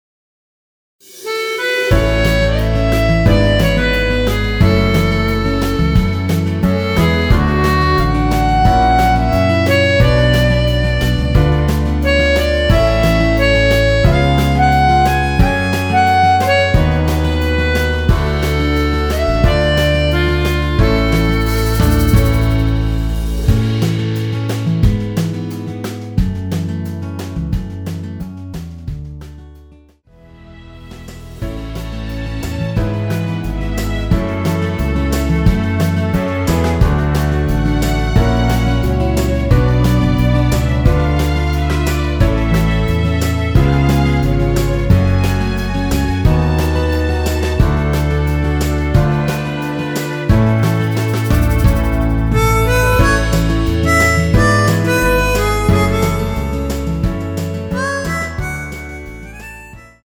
원키에서(-1)내린 MR입니다.
앞부분30초, 뒷부분30초씩 편집해서 올려 드리고 있습니다.